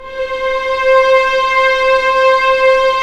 Index of /90_sSampleCDs/Roland LCDP13 String Sections/STR_Violins IV/STR_Vls7 p Orch